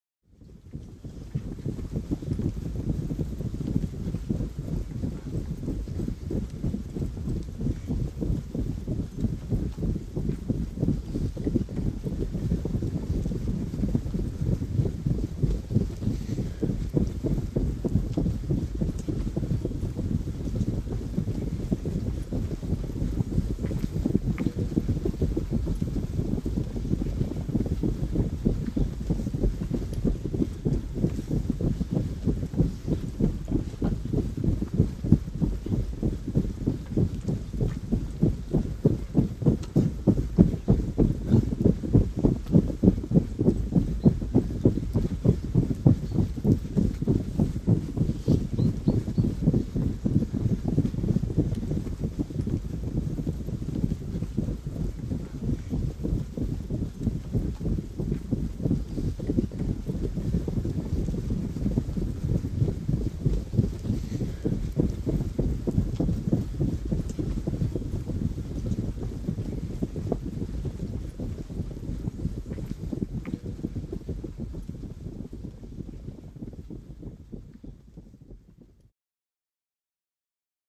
Infantry Charge | Sneak On The Lot
Infantry Running Constant; 200 Men Run Constant On Hard Mud And Grass Surface, Feet Only, With Light Breaths